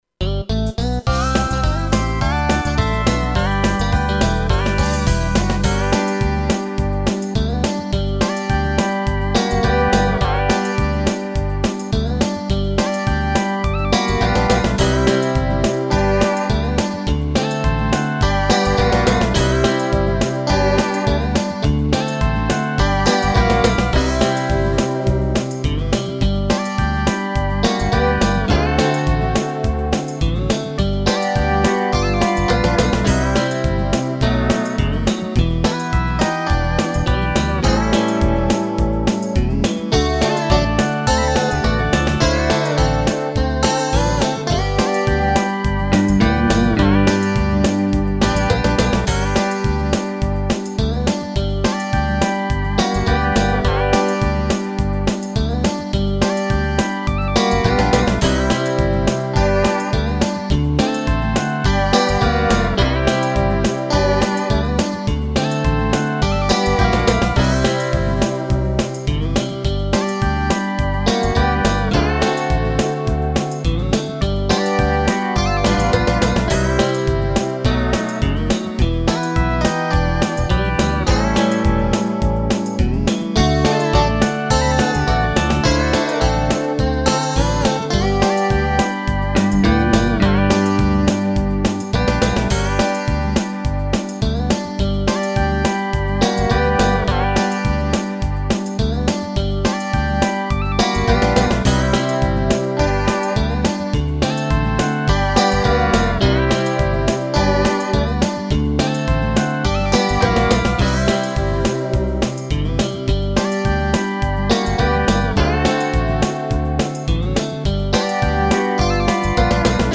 Скачать минус детской песни
🎶 Детские песни